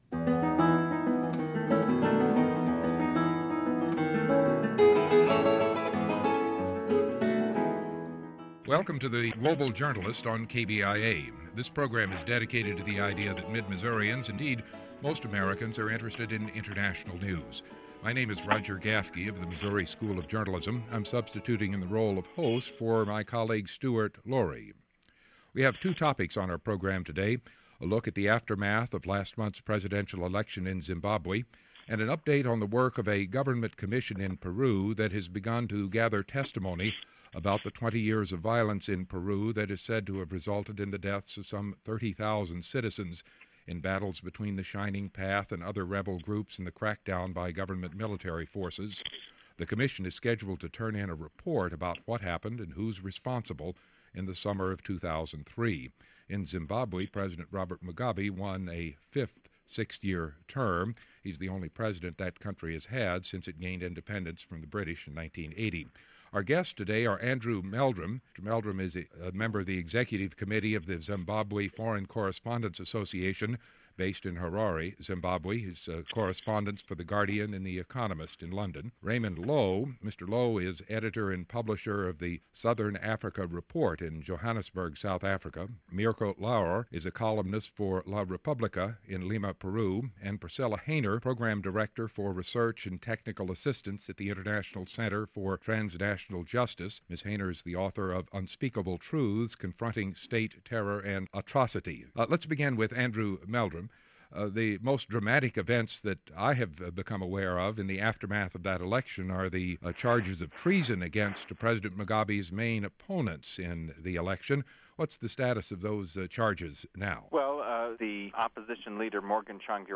He, three journalists and one human rights activist go deep into the meaning, functionality and outcomes of truth commissions in the context of transitional justice, taking the examples of Peru and South Africa and pondering what it would look like if applied in Zimbabwe – which would require Robert Mugabe’s removal as President.